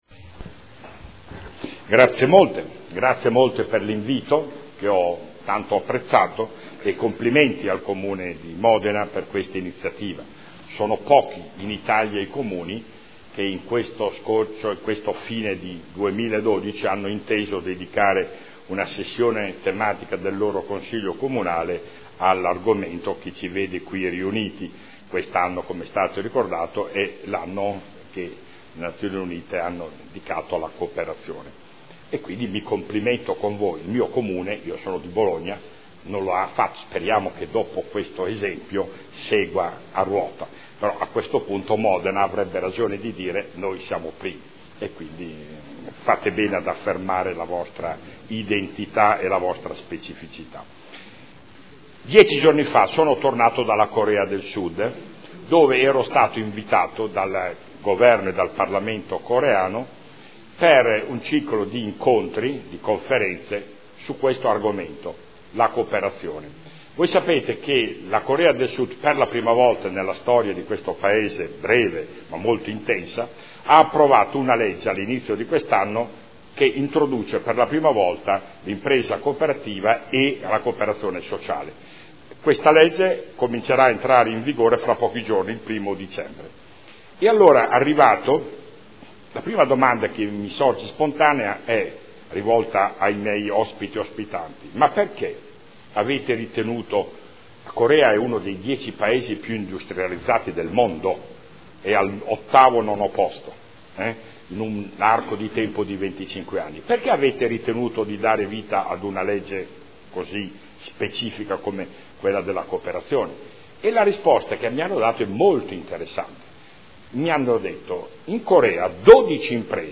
Stefano Zamagni — Sito Audio Consiglio Comunale
Intervento su celebrazione dell’Anno internazionale delle cooperative indetto dall’ONU per il 2012